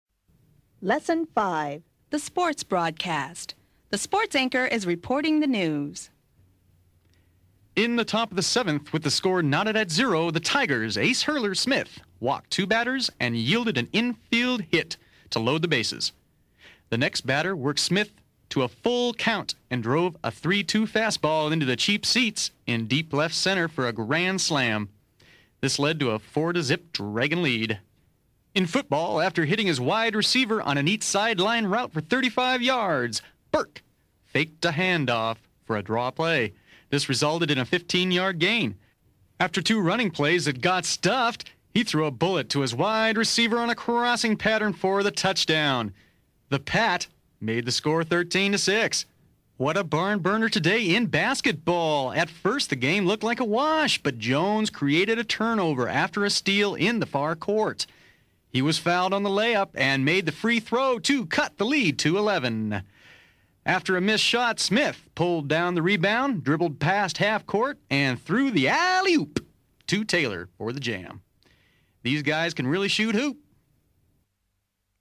The sports anchor is reporting the news.